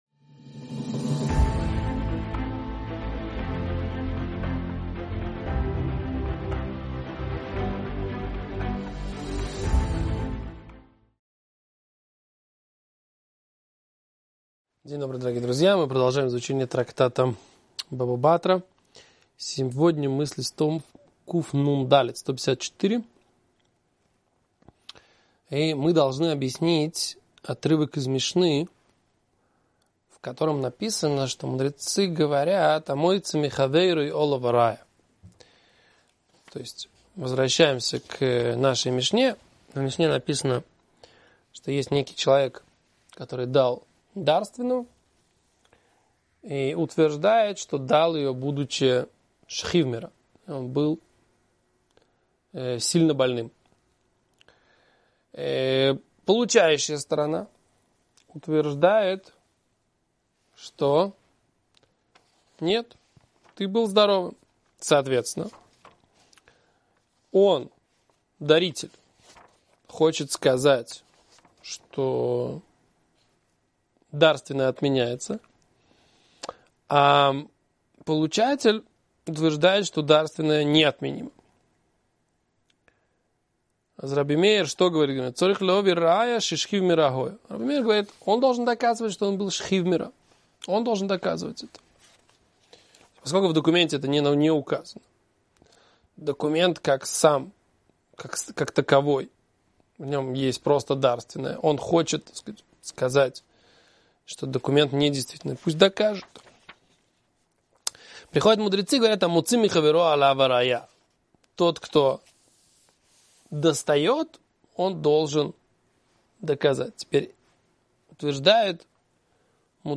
Урок